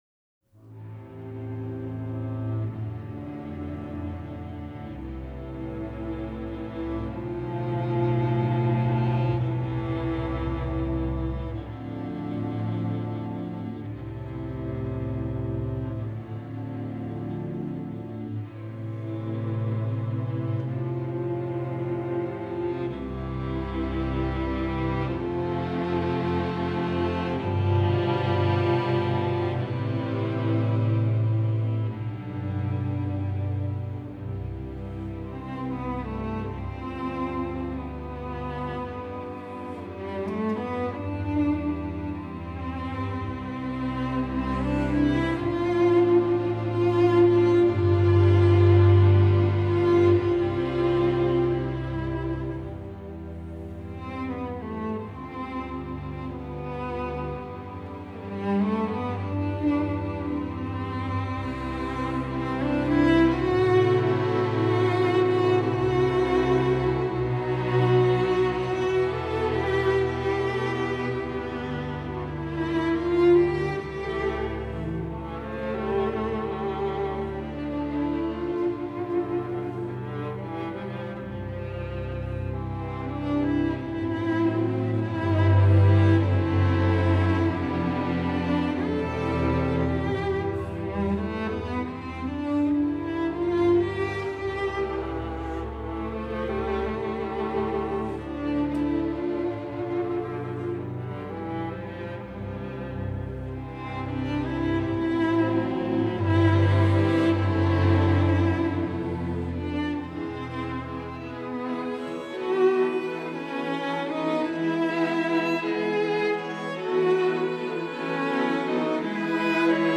Voicing: String Or